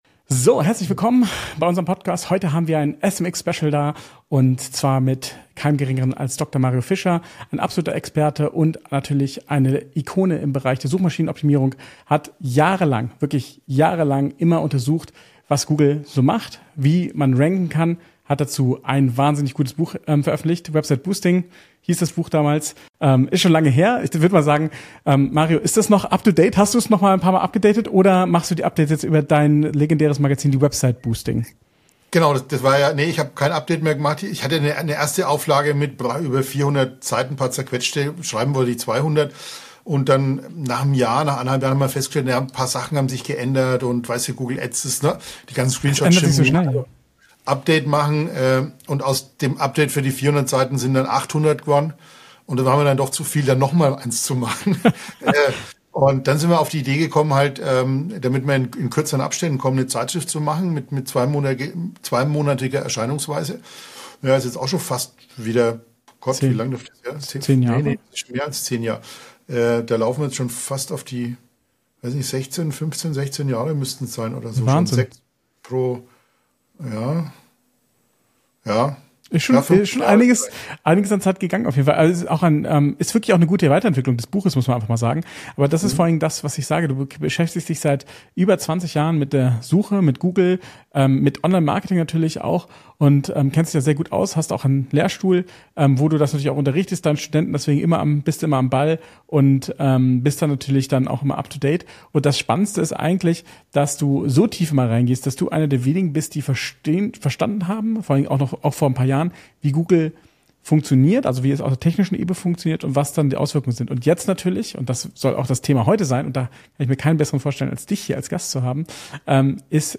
SMX Special: Schockierende SEO-Wahrheiten: Was Google wirklich rankt | Interview